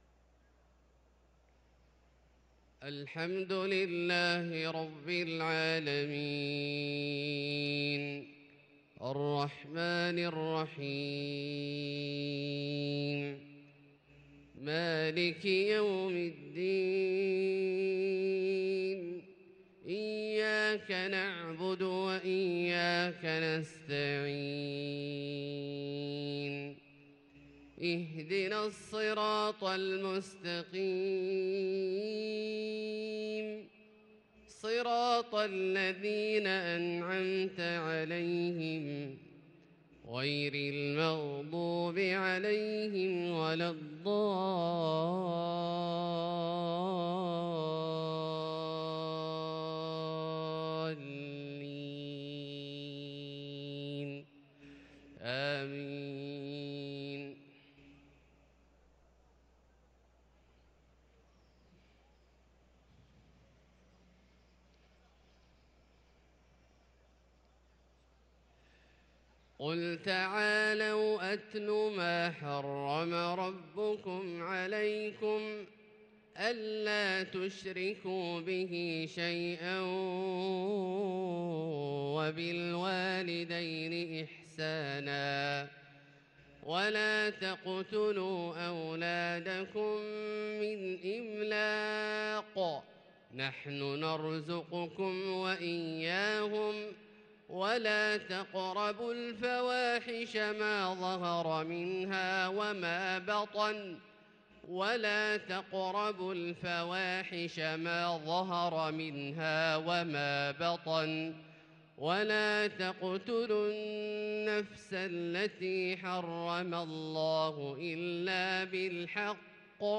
صلاة الفجر للقارئ عبدالله الجهني 24 جمادي الأول 1444 هـ
تِلَاوَات الْحَرَمَيْن .